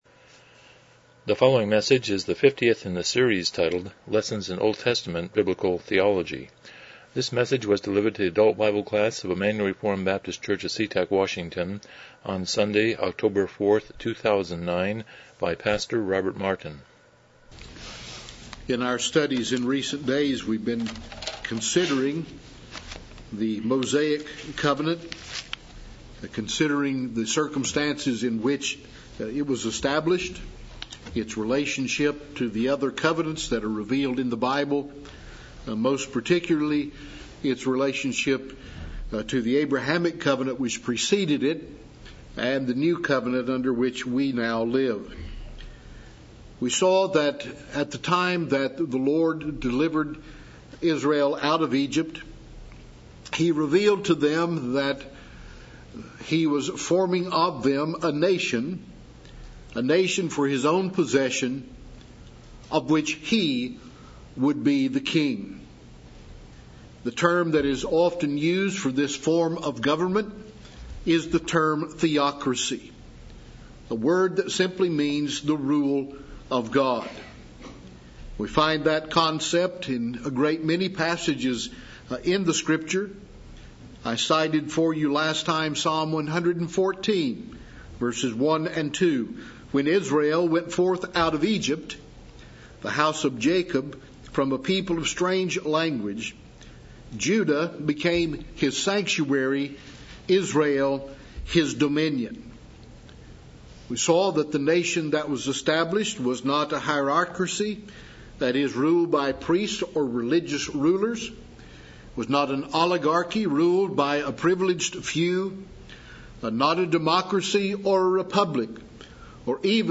Series: Lessons in OT Biblical Theology Service Type: Sunday School « 76 Chapter 14.1